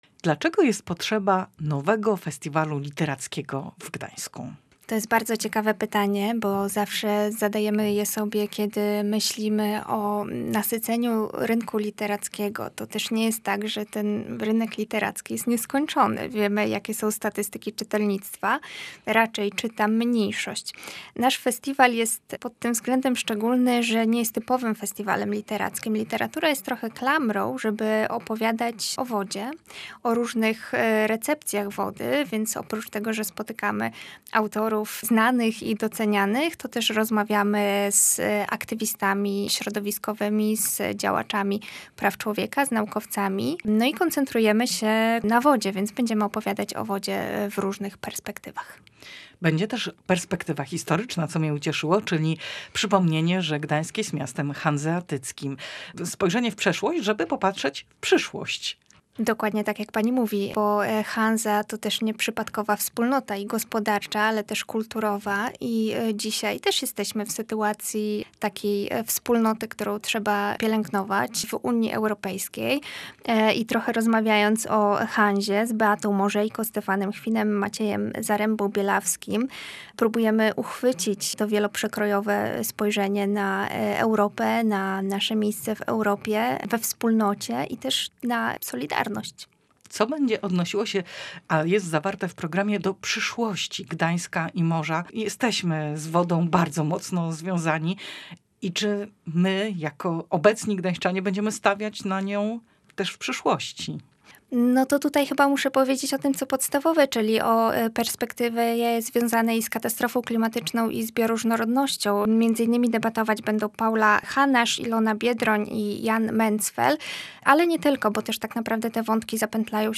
Posłuchaj materiału naszej reporterki: https